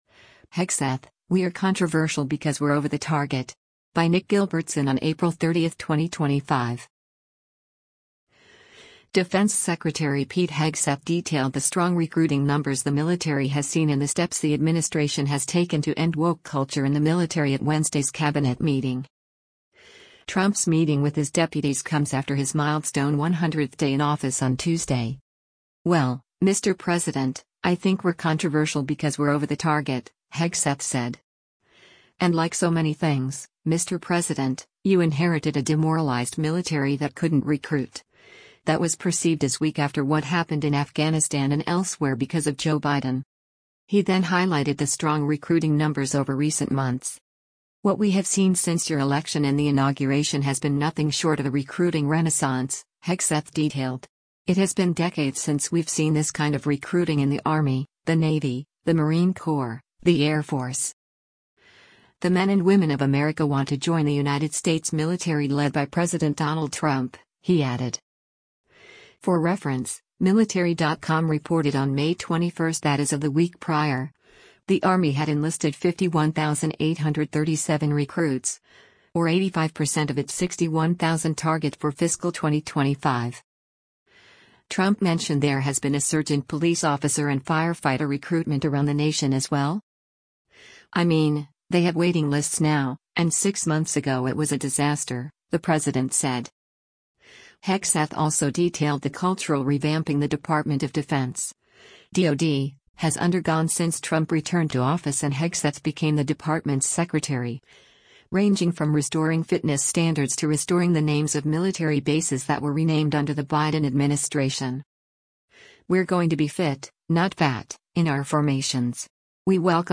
Defense Secretary Pete Hegseth detailed the strong recruiting numbers the military has seen and the steps the administration has taken to end woke culture in the military at Wednesday’s Cabinet meeting.